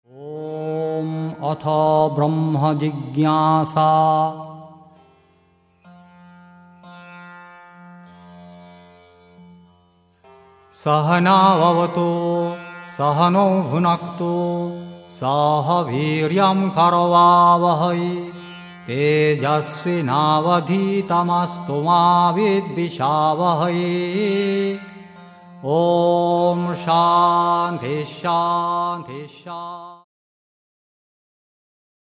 (Vedic mantras in chant and song)